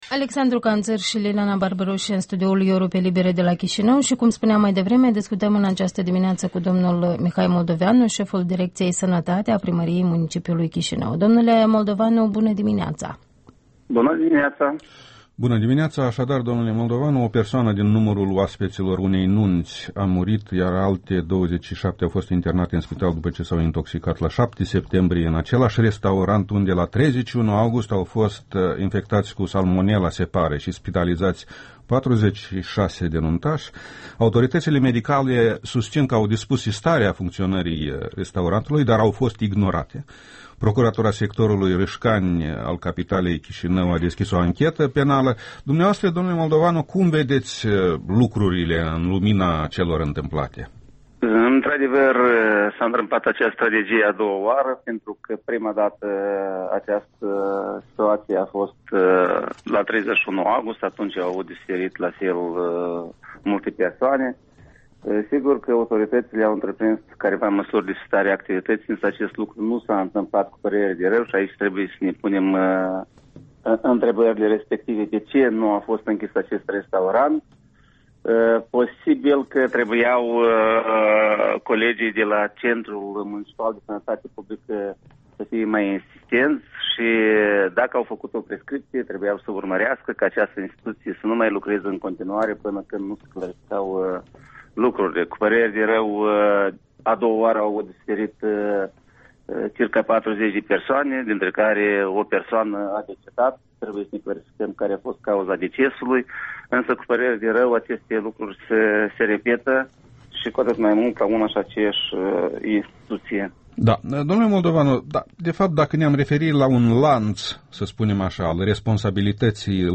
Interviul dimineții: cu Mihai Moldovanu, şeful Direcției Sănătate a Primăriei Municipiului Chișinău